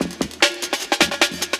61 LOOP04.wav